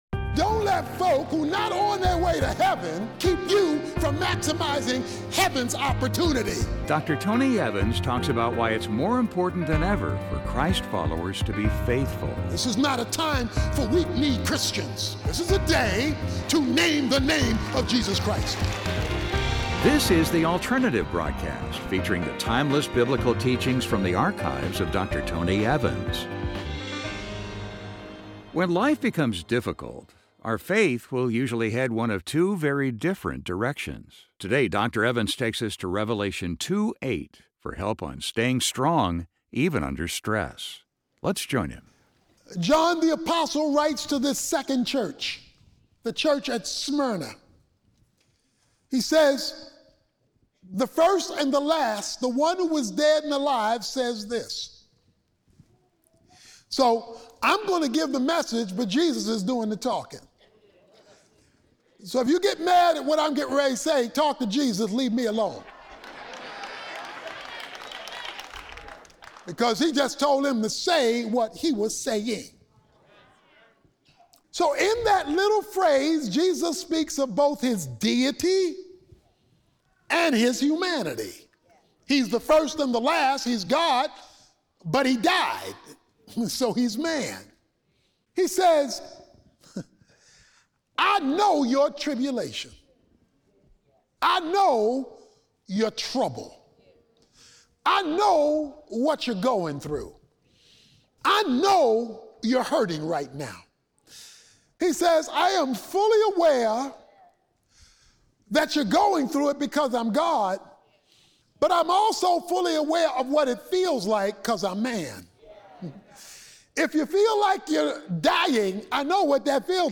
When life becomes difficult, our faith will usually head one of two very different directions. In this message, Dr. Tony Evans explores a powerful passage in the book of Revelation that reveals how we can find the strength to stand strong, even while under extreme stress.